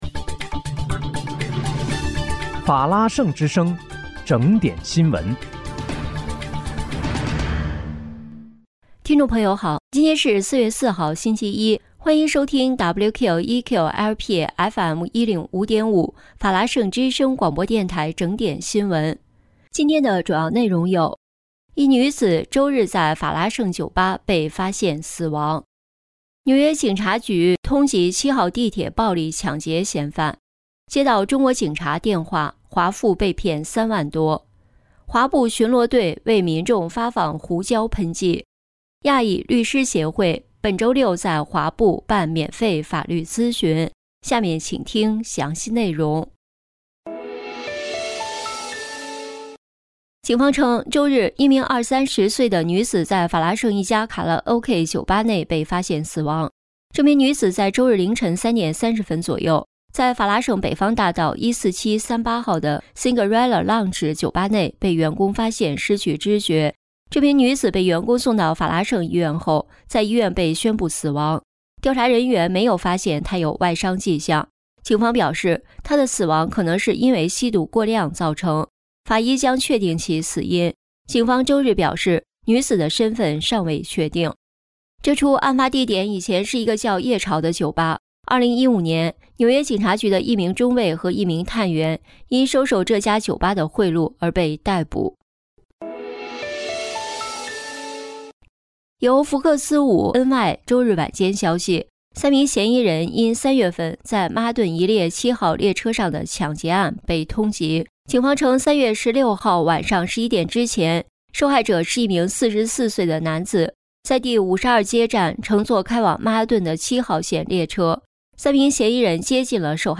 4月4日（星期一）纽约整点新闻
听众朋友您好！今天是4月4号，星期一，欢迎收听WQEQ-LP FM105.5法拉盛之声广播电台整点新闻。